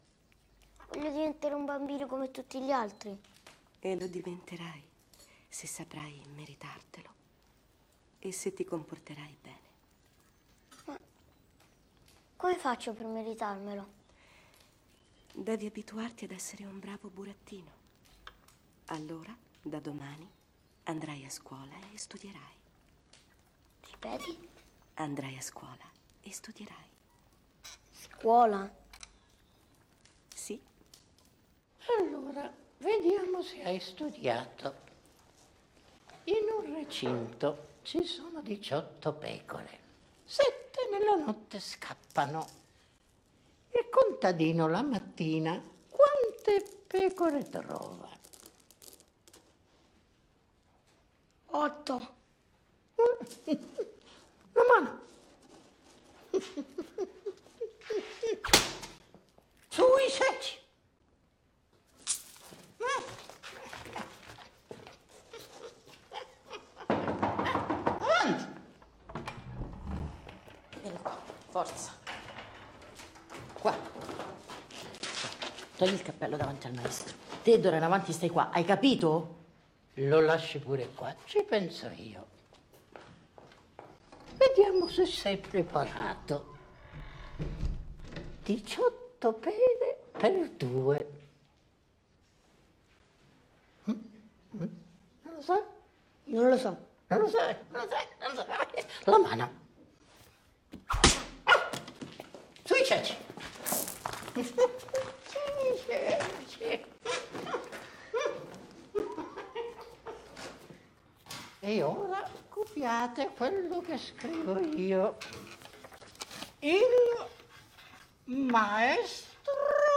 A math school scene in the Italian movie Pinoccio: Pinoccio: I want to become a kid like everyone else.